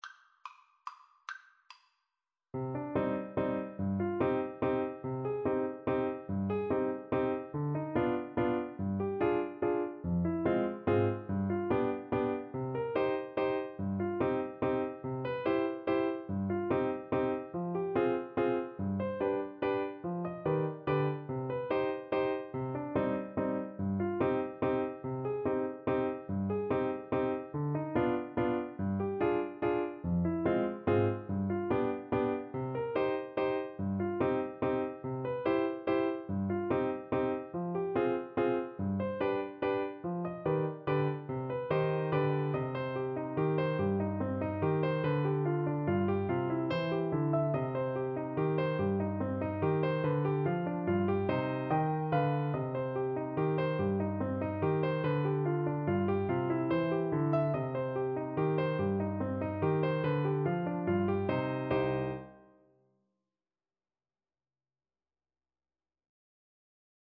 • Unlimited playalong tracks
C minor (Sounding Pitch) (View more C minor Music for Trombone )
Molto Allegro = c.144 (View more music marked Allegro)
3/4 (View more 3/4 Music)
Traditional (View more Traditional Trombone Music)